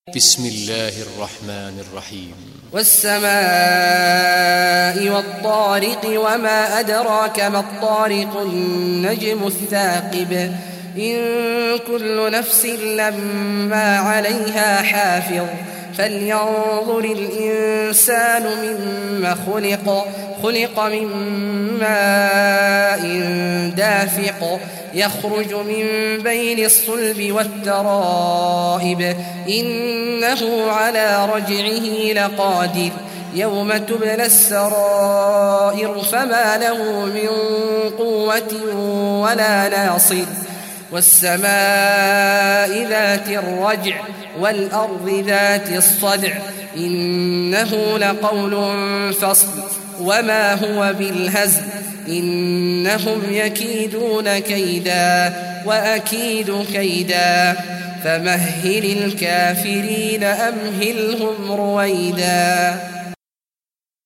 Surah Tariq Recitation by Sheikh Awad Juhany
Surah Tariq, listen or play online mp3 tilawat / recitation in Arabic in the beautiful voice of Sheikh Abdullah Awad al Juhany.